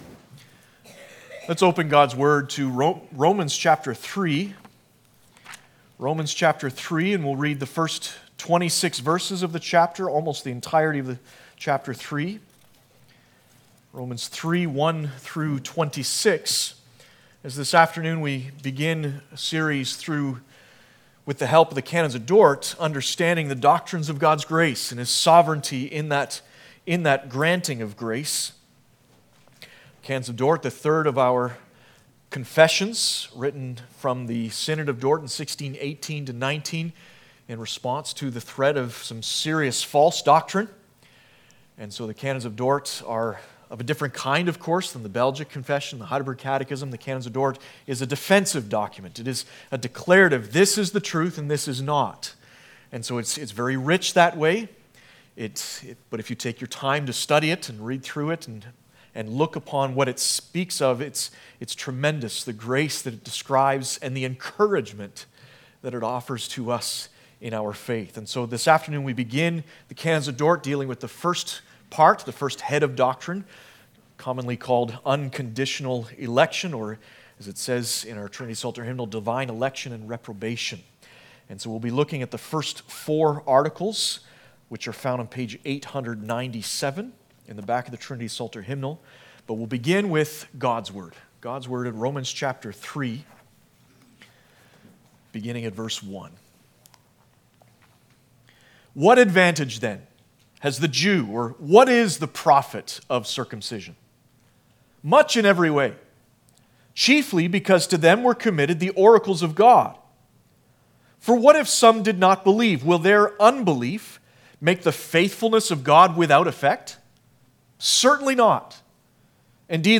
Passage: Romans 3:1-26, Canon of Dort I.1-4 Service Type: Sunday Afternoon